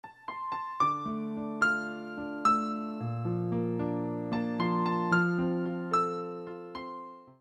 Рингтоны на СМС